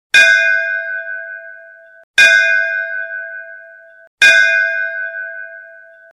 File Category : Free mobile ringtones > > Sms ringtones